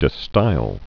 (də stīl, stāl)